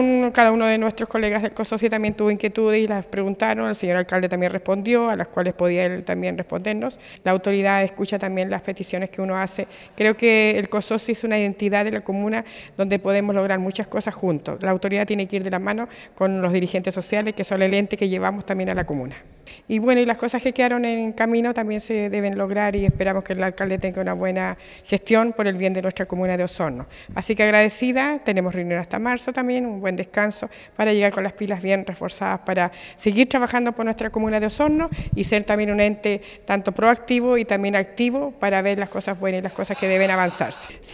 El miércoles recién pasado, la sala de sesiones del municipio de Osorno fue el escenario de la primera reunión del año del Consejo de Organizaciones de la Sociedad Civil, COSOCI.